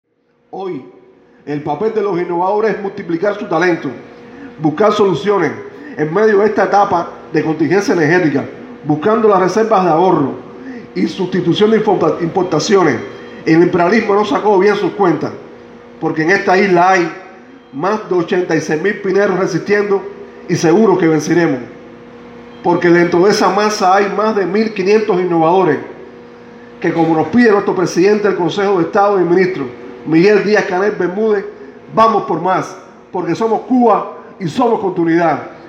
En el acto de inicio de la Jornada del Innovador en la Isla de la Juventud y como homenaje al Guerrillero Heroico, impulsor de tan importante movimiento